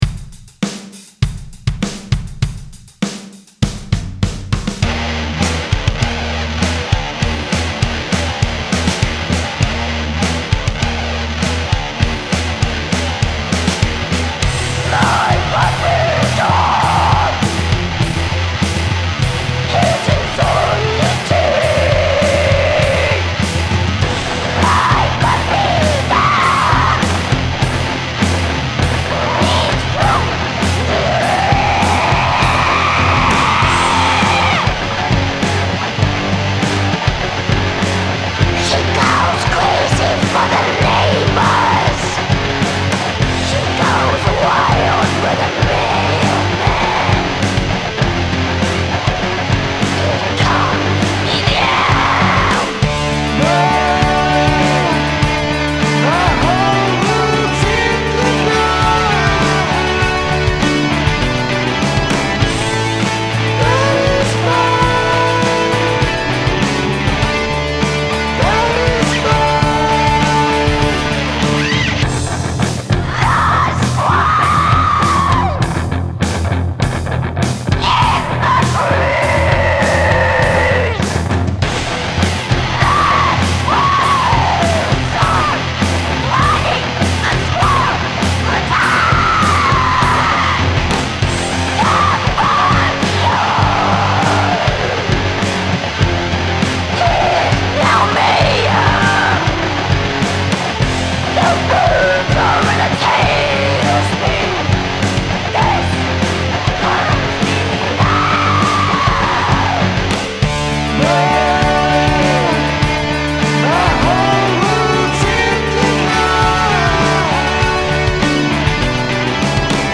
- Riffs, guitars, Blackened Death Metal Vocals, 1st solo
-Bass, guitars, lame pop-metal Vocals, trem picking solo